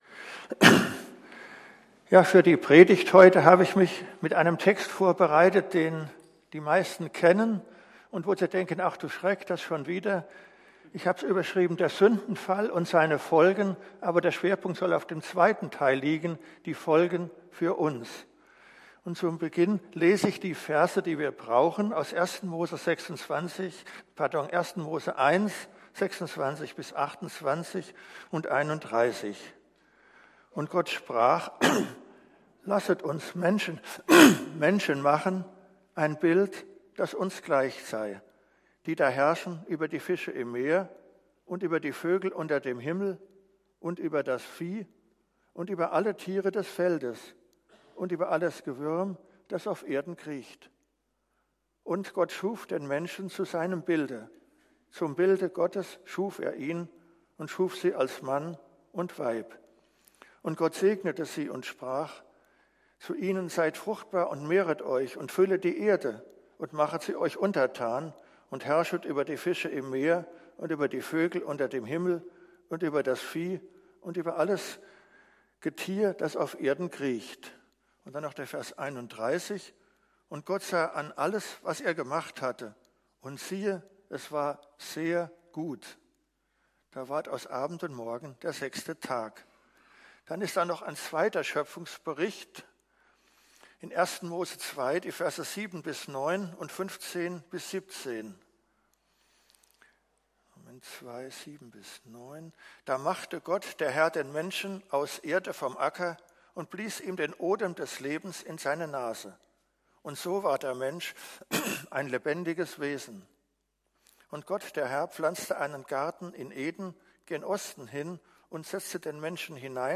Predigt
predigt.mp3